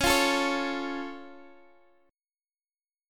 Db+ Chord
Listen to Db+ strummed